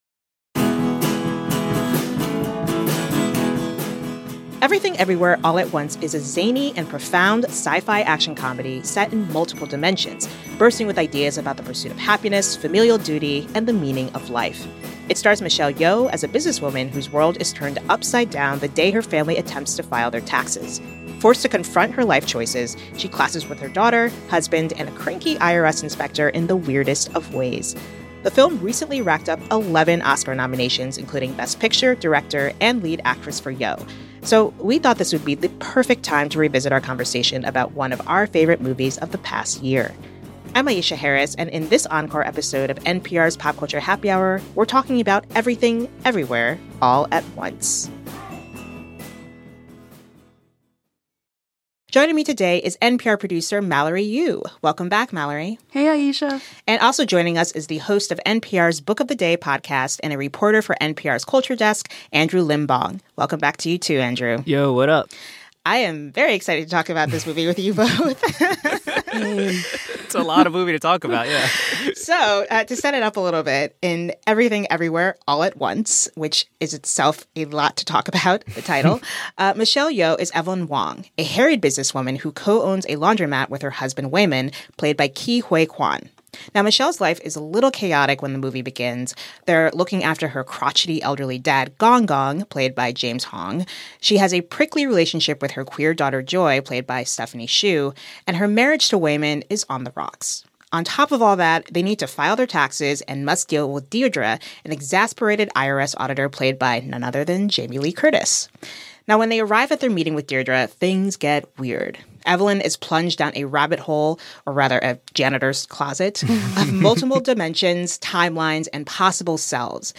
So we thought this would be the perfect time to revisit our conversation about one of our favorite movies of the past year.
Movie Review